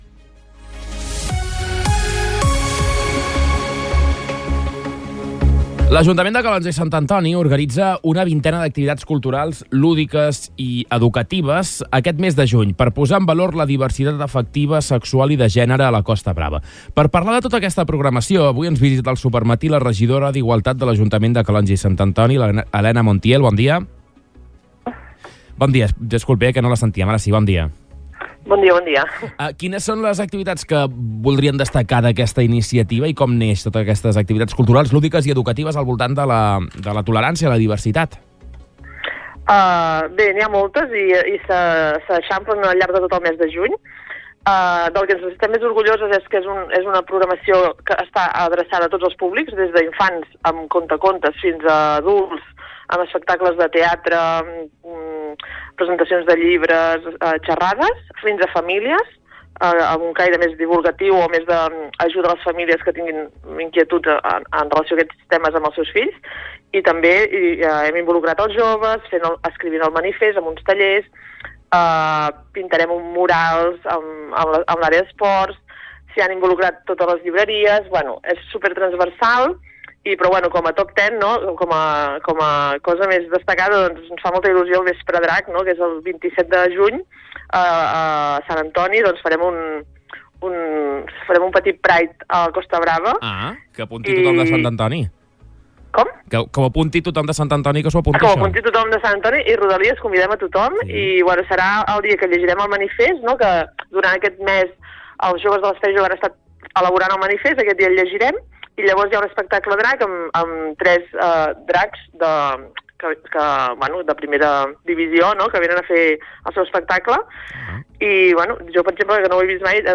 Entrevistes
La regidora d’Igualtat, Elena Montiel, ha explicat en una entrevista al programa Supermatí que la iniciativa busca arribar a tots els públics, des d’infants fins a adults.